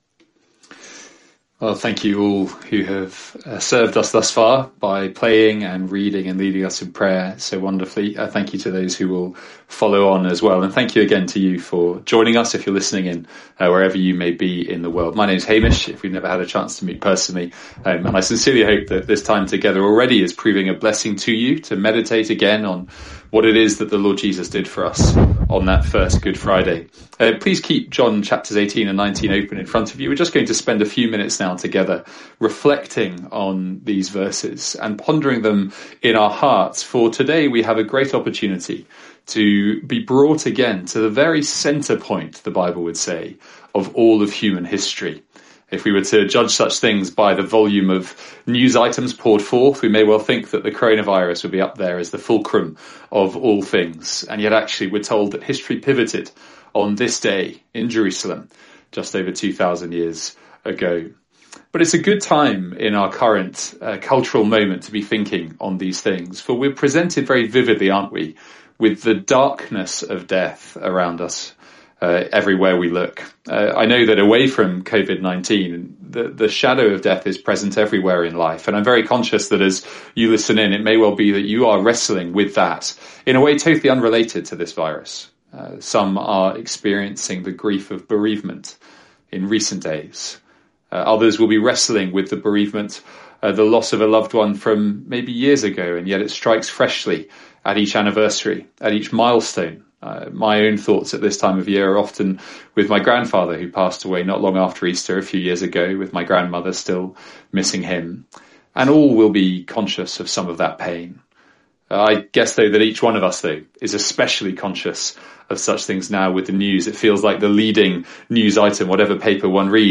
The address from our Good Friday Service.